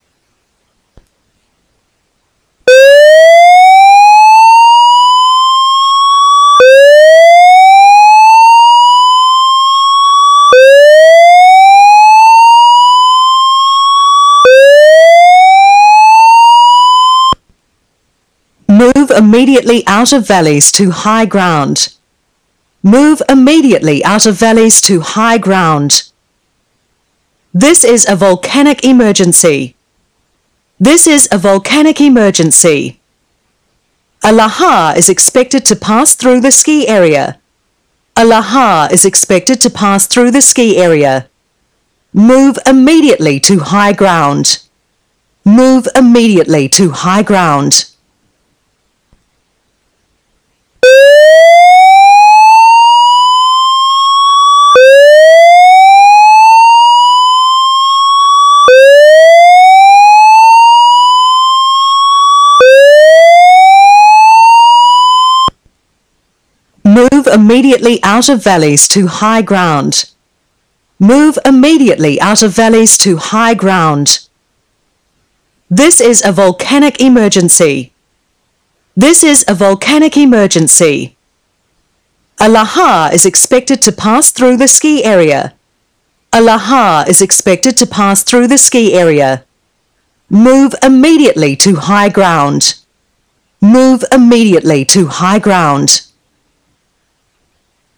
If an eruption is detected at Ruapehu, a siren and voice message warning will sound in areas at risk from lahars at Whakapapa Ski Area. It signals visitors to move out of lahar paths to higher ground on ridges.
If you hear WLAWS sirens (9,187K, WAV) and voice messages in Whakapapa ski area:
reds-wlaws-lahar-warning.wav